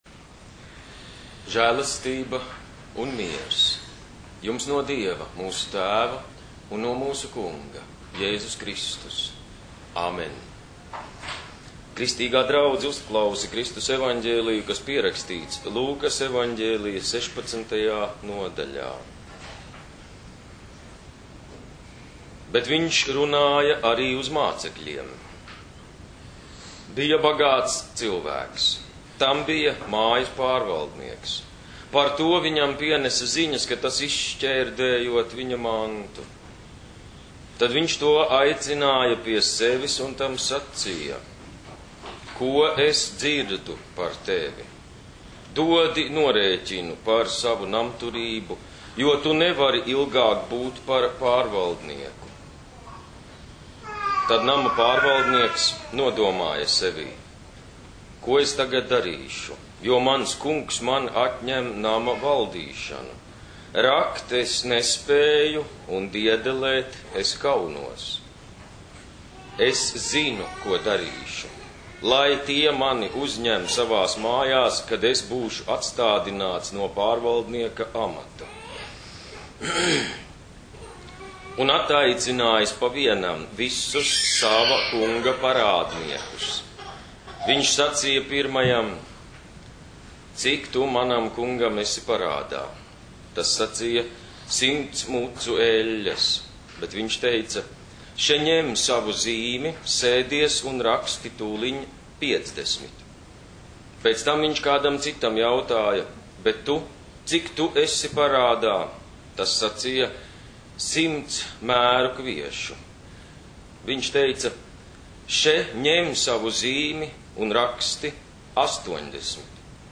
audio sprediķis